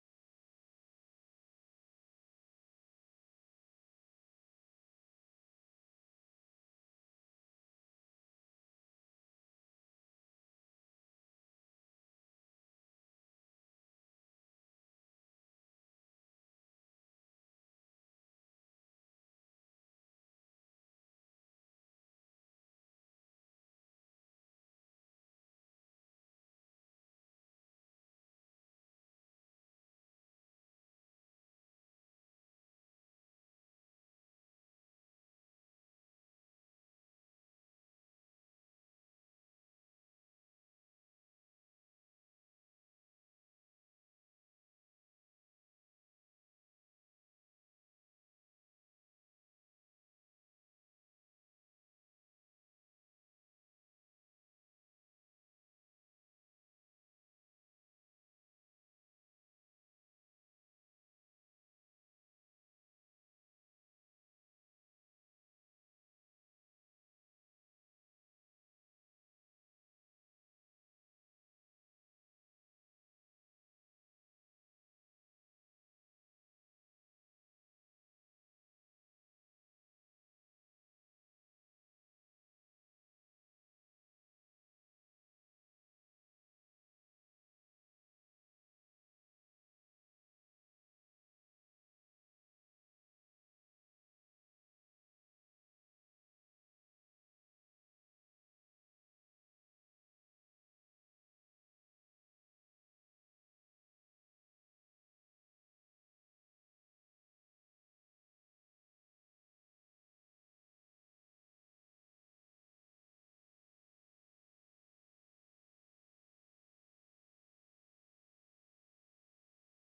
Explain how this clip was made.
It was late in the evening and, well, Mondays (which is when this was recorded) are always my toughest days ;;;; Please enjoy!